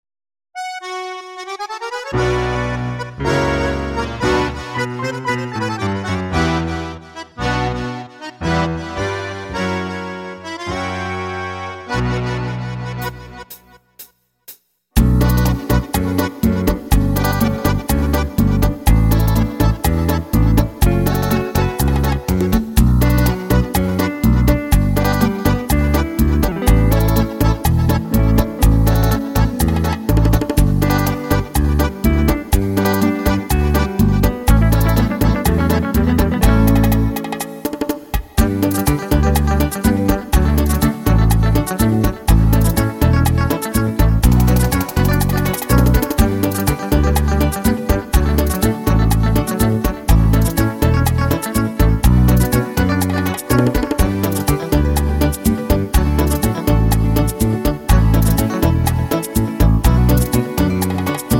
echter Bachata